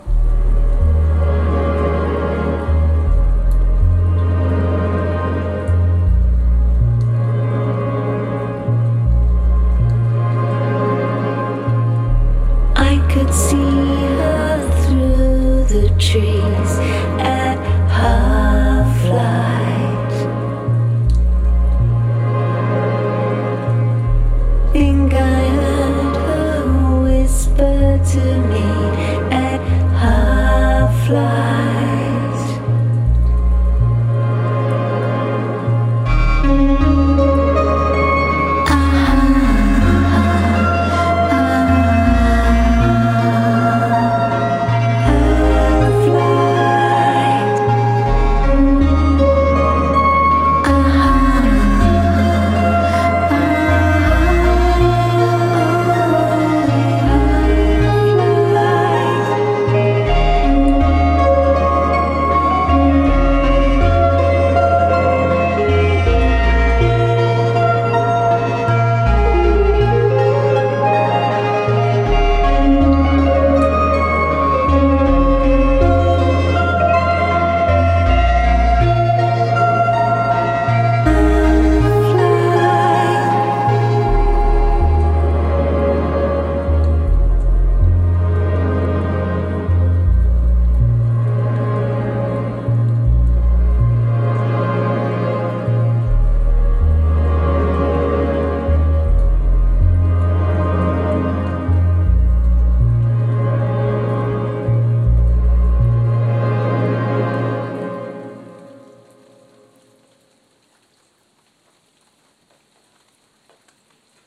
кто предпочитает эмбиент
Альтернативно-танцевальная группа 1990-х